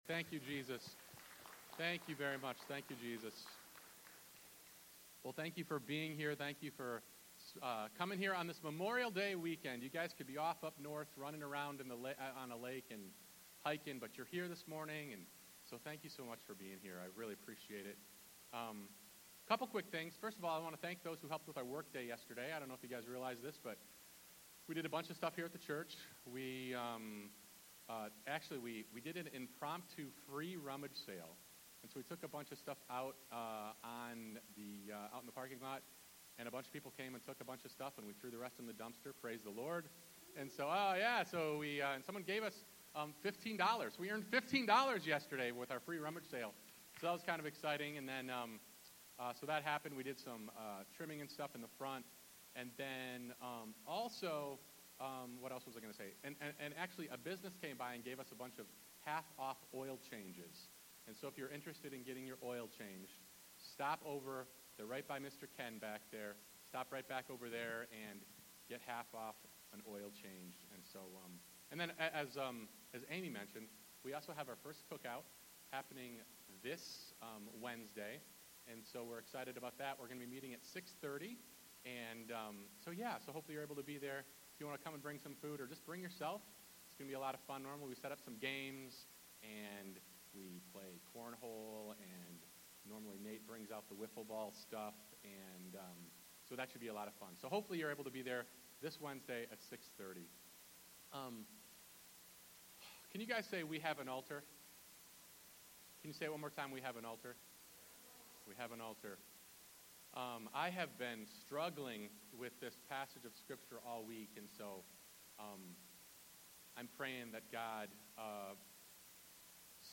Sunday Service.